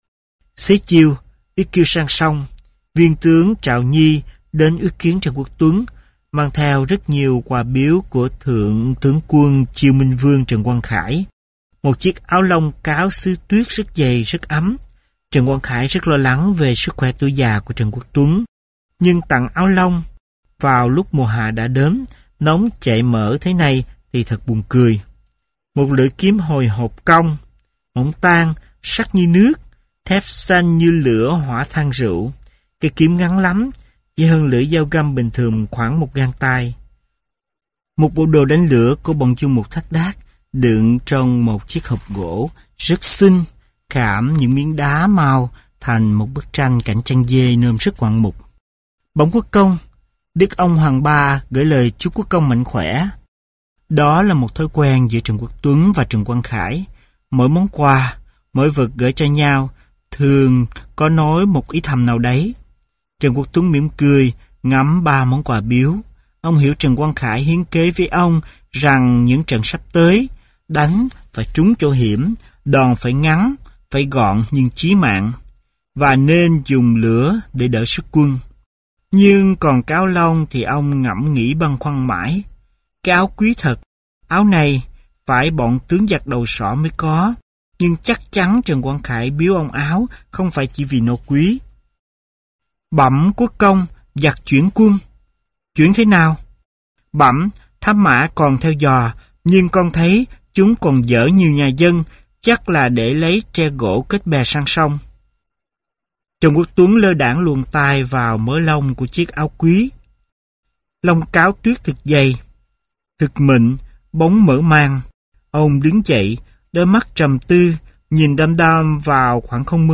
Sách nói Trăng Nước Chương Chương Dương - Hà Ân - Sách Nói Online Hay